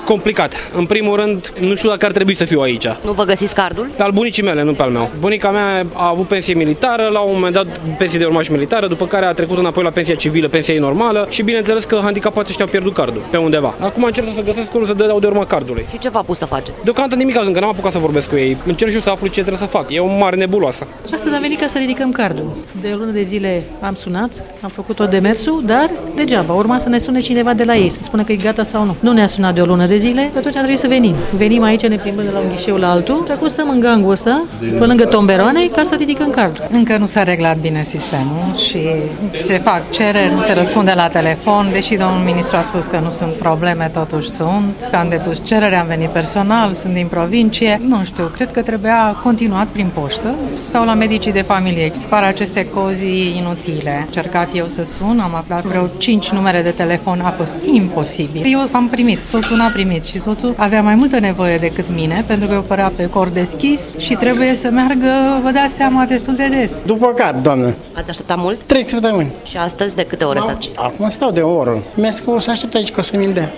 Ce spun oamenii care stau la coadă la Casa de Asigurări de Sănătate a Apărării, Ordinii Publice, Siguranţei Naţionale şi Autorităţii Judecătoreşti?
vox-carduri-opsnaj-13-mai.mp3